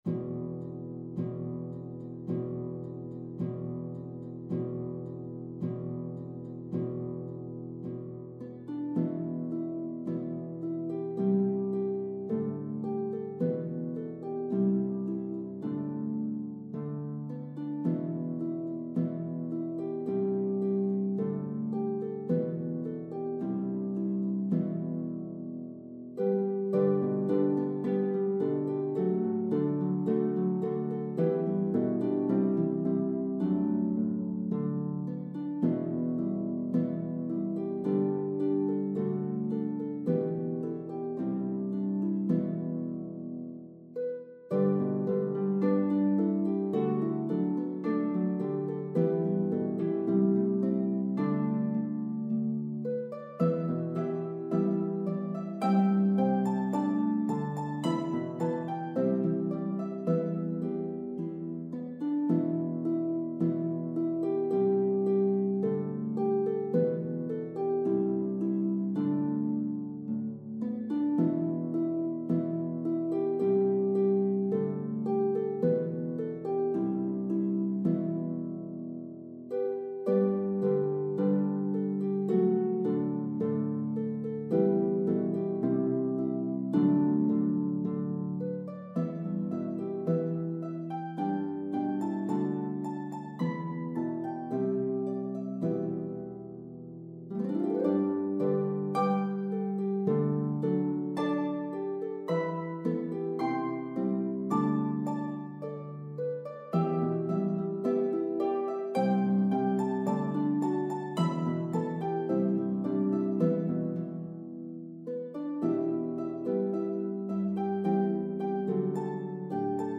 This pentatonic melody dates back to the Middle Ages.
The piece starts and ends with knocking on the soundboard.